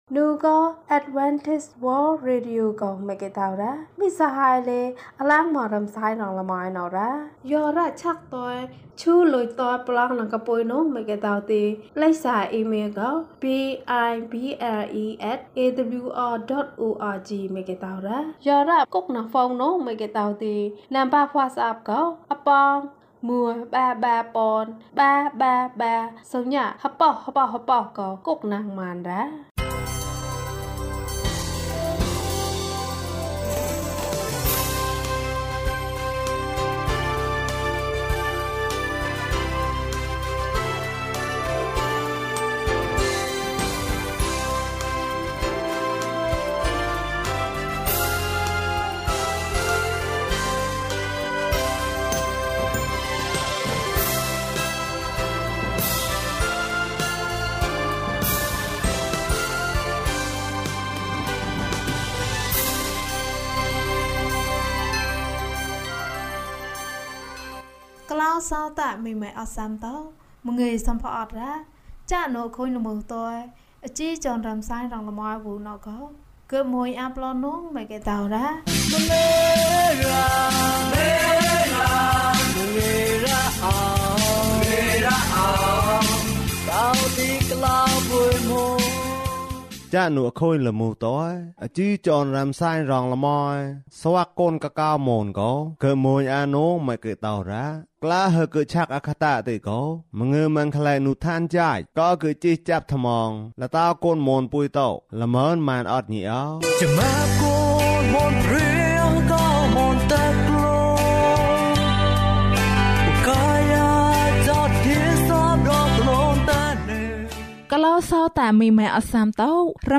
ဘုရားသခင်သည် ကျွန်ုပ်၏အသက်ကို ကယ်တင်တော်မူပါ။၀၁ ကျန်းမာခြင်းအကြောင်းအရာ။ ဓမ္မသီချင်း။ တရားဒေသနာ။